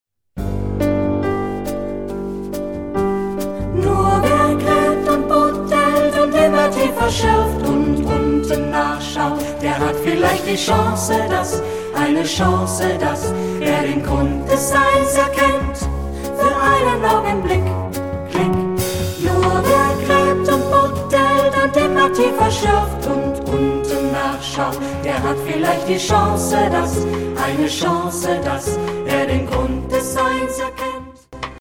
Tonalité : mi mineur